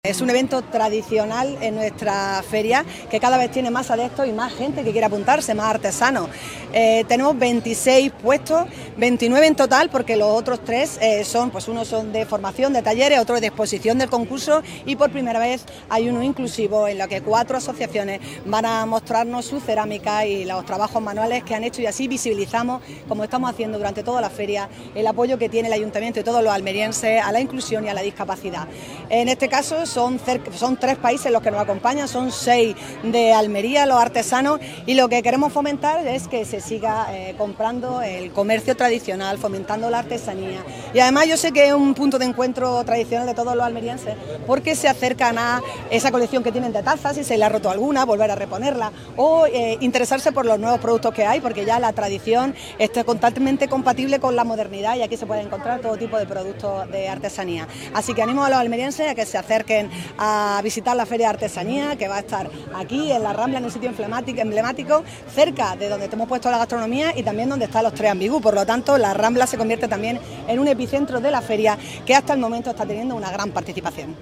La alcaldesa, María del Mar Vázquez, inaugura la 33 Feria de Almería Tradicional y Cerámica, que incluye talleres familiares y un concurso